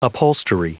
Prononciation du mot upholstery en anglais (fichier audio)
Prononciation du mot : upholstery